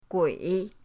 gui3.mp3